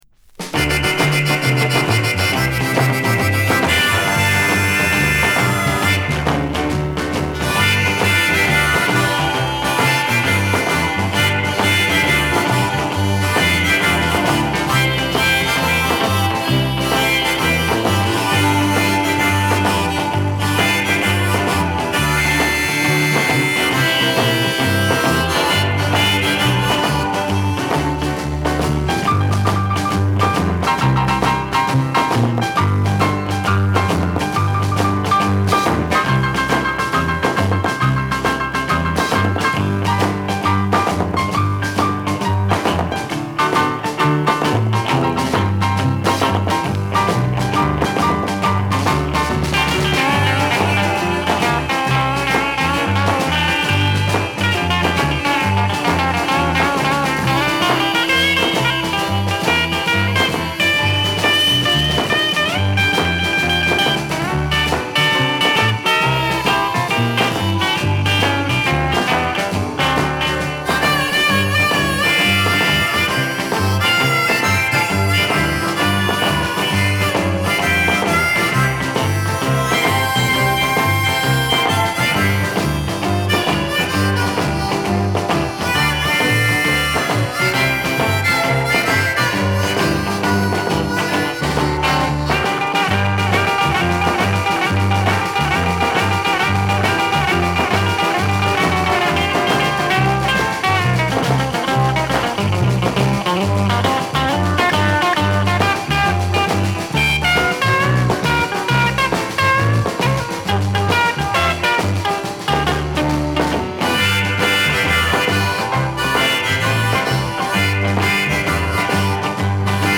ウィスコンシン州ミルウォーキー出身のロックンロール・コンボ。